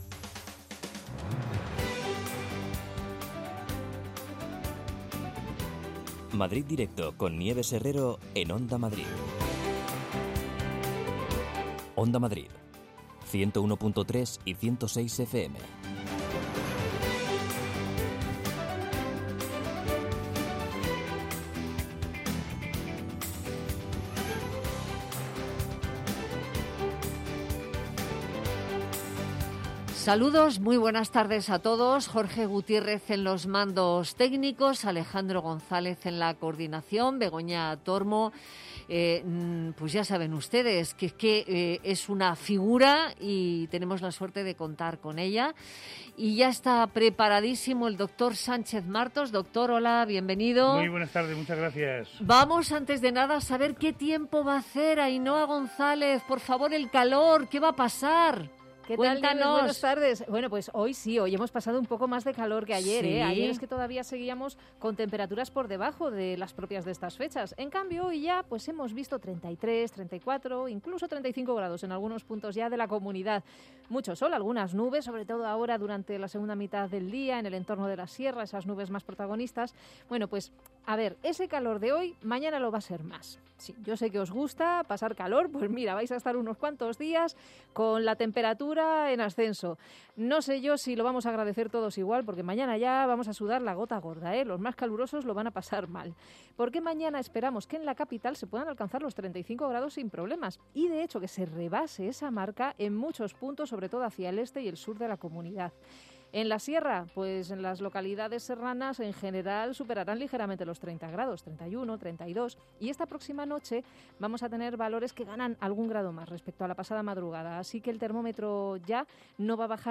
Nieves Herrero se pone al frente de un equipo de periodistas y colaboradores para tomarle el pulso a las tardes. Cuatro horas de radio donde todo tiene cabida. La primera hora está dedicada al análisis de la actualidad en clave de tertulia.